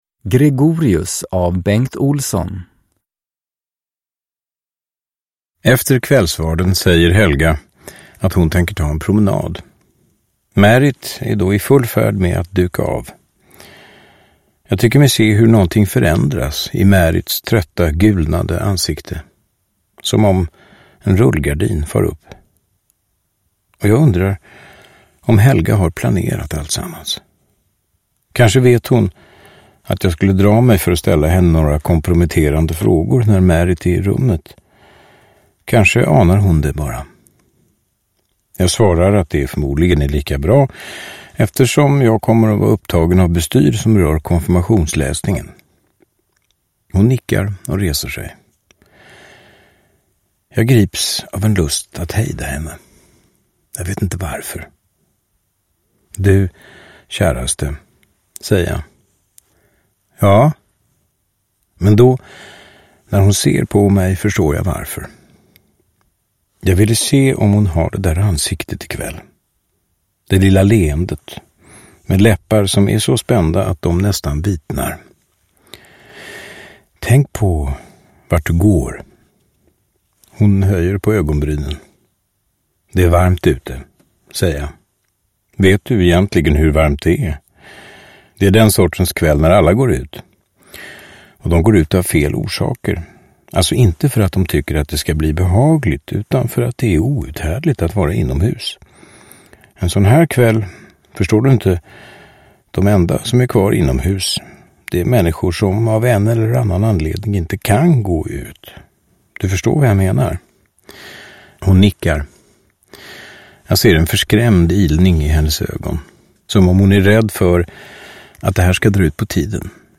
Gregorius – Ljudbok – Laddas ner
Uppläsare: Johan Rabaeus